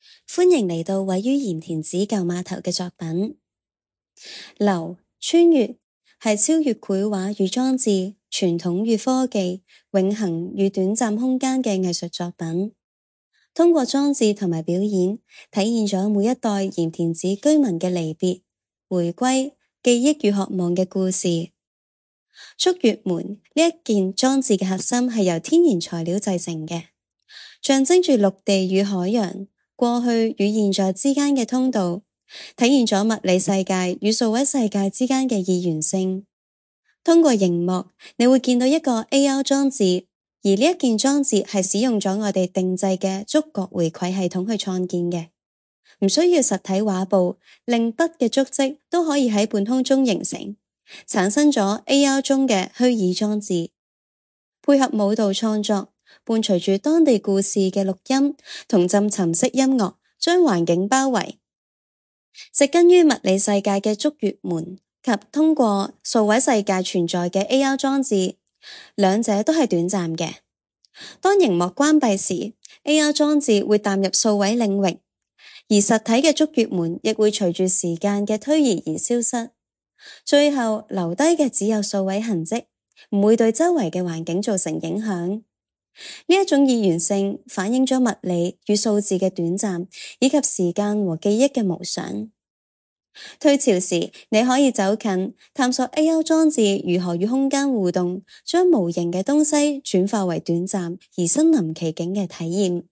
《流 穿越》— 藝術家原聲介紹按此閱讀原聲介紹文字稿